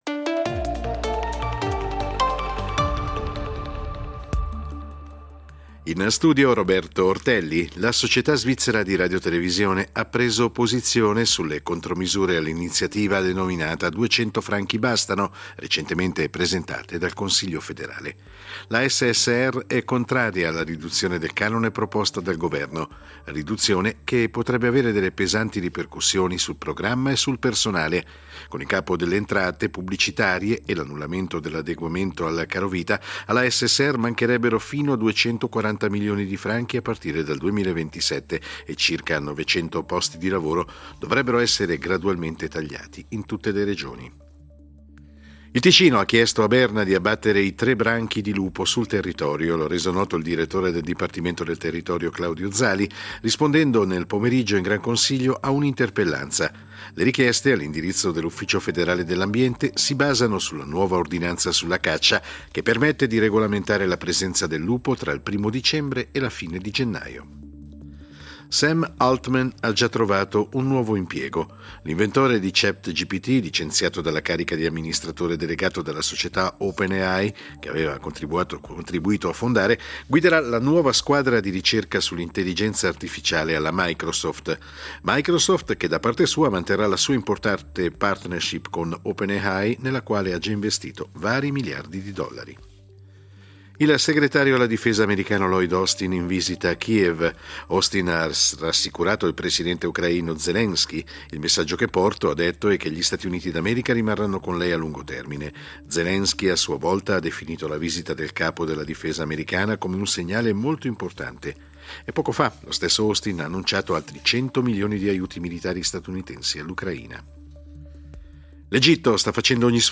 Notiziario delle 20:00 del 20.11.2023